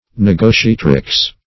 Search Result for " negotiatrix" : Wordnet 3.0 NOUN (1) 1. a woman negotiator ; [syn: negotiatress , negotiatrix ] The Collaborative International Dictionary of English v.0.48: Negotiatrix \Ne*go`ti*a"trix\, n. [L.] A woman who negotiates.
negotiatrix.mp3